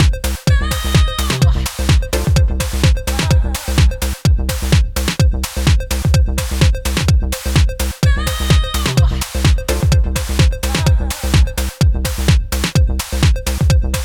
I recorded a clip straight from the Tangerine, and also the same clip but from the Tangerine through the nts-3 with effects off.
does feel like theNTS-3 has dulled transients a bit maybe… interesting!